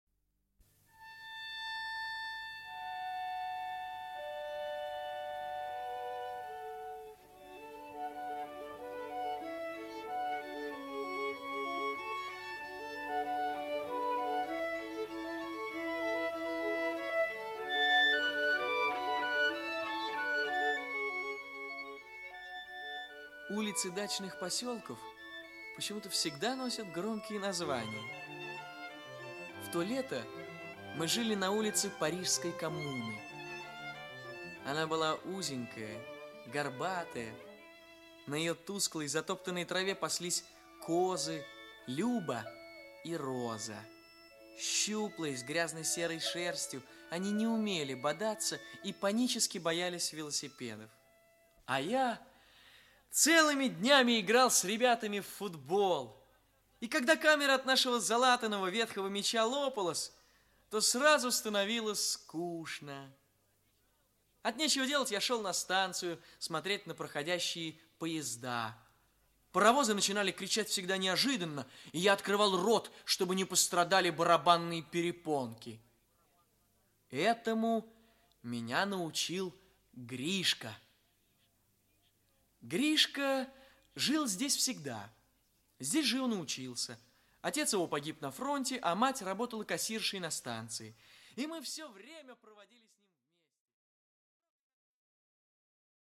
Aудиокнига Станция первой любви Автор Владимир Амлинский Читает аудиокнигу Олег Табаков.